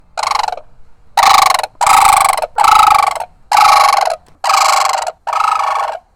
This .wav file is from a Crane at Jungle Island - Miami.
Crane_Final.wav